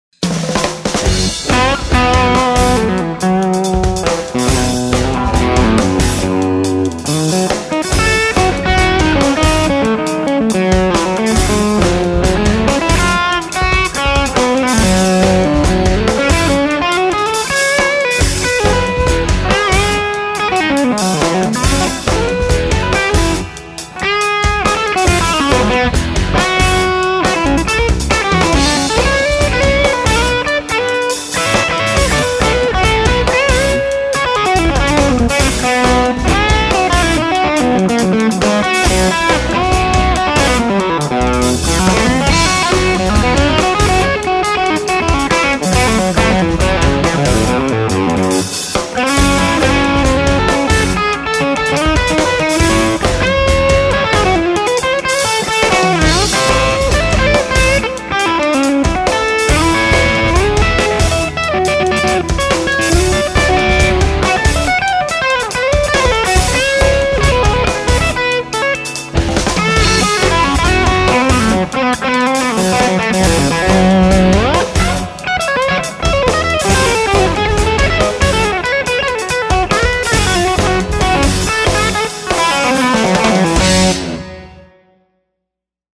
G1265 speaker. FatWerks clips have more gain than the Pimp clips. 200ms delay in mix for the pimps.